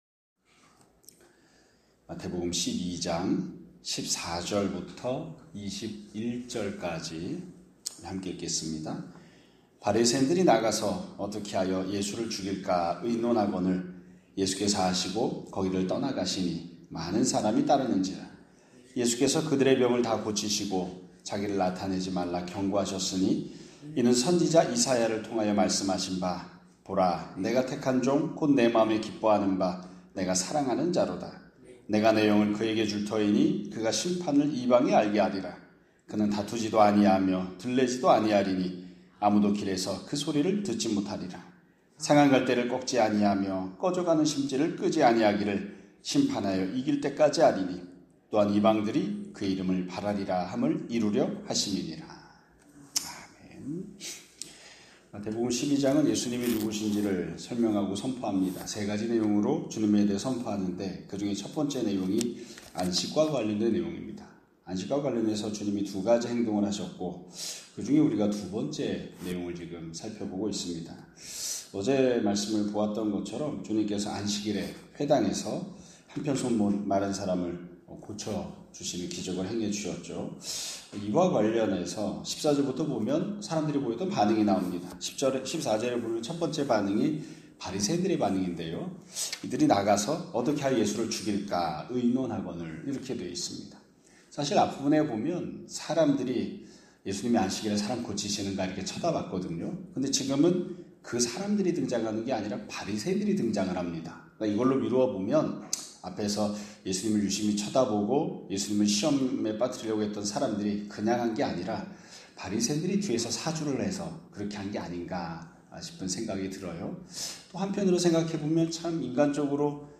2025년 9월 11일 (목요일) <아침예배> 설교입니다.